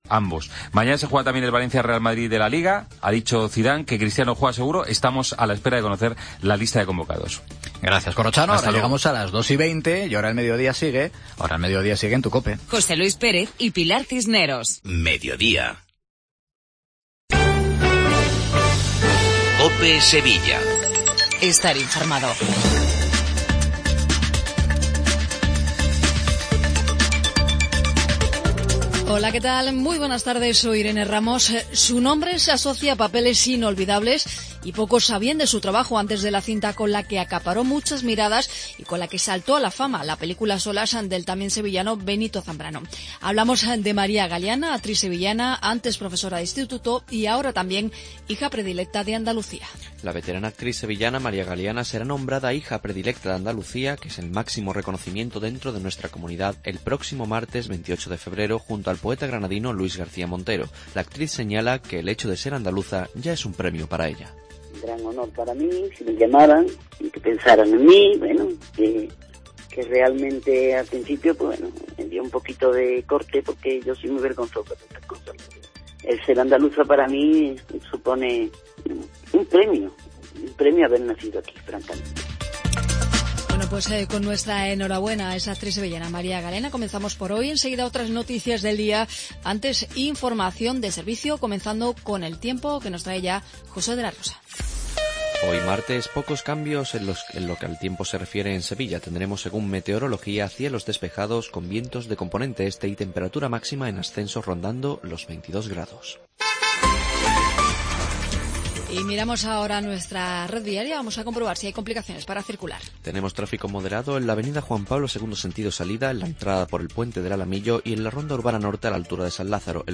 INFORMATIVO LOCAL MEDIODIA COPE SEVILLA